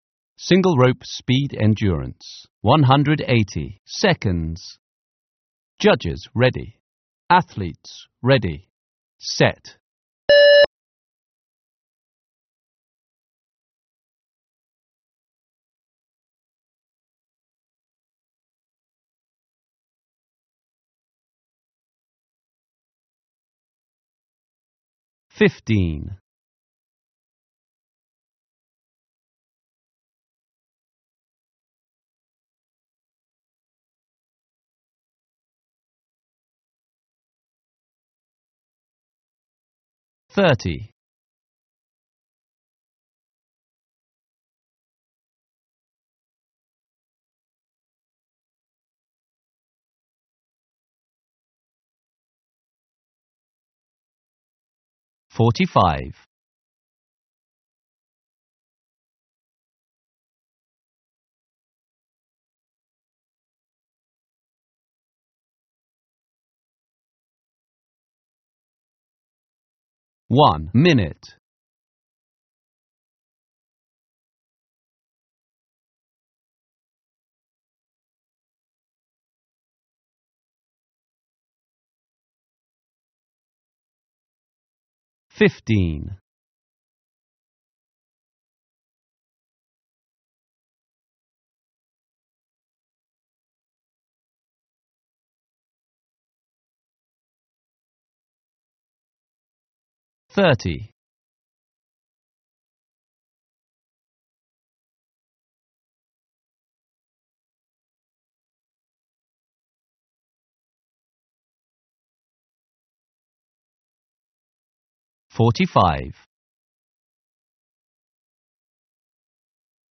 štartovacie povely
Na konci zaznelo slovo STOPNa konci zaznie beeb (pípnutie)